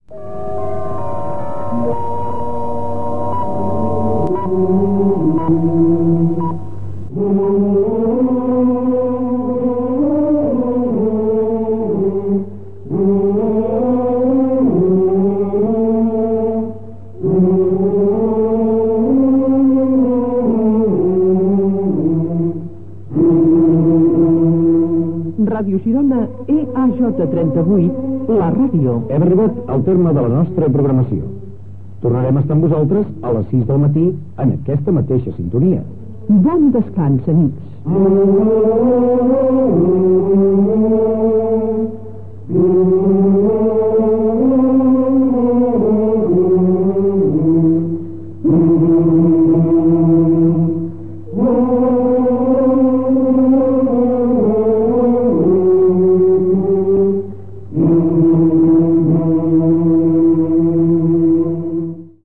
e2c4b73baf27b2a08ae8d5f9ed7f7703e3866b99.mp3 Títol Ràdio Girona Emissora Ràdio Girona Cadena SER Titularitat Privada estatal Descripció Tancament d'emissió.